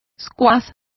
Also find out how squash is pronounced correctly.